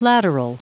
Prononciation du mot lateral en anglais (fichier audio)
Prononciation du mot : lateral